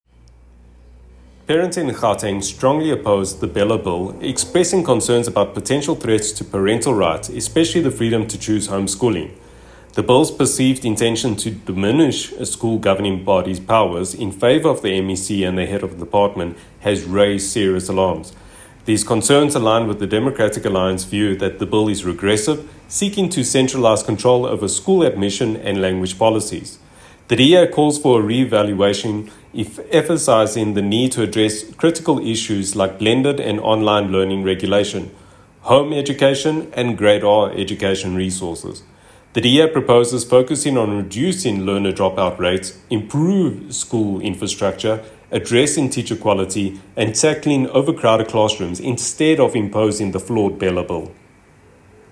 soundbite by Sergio Isa dos Santos MPL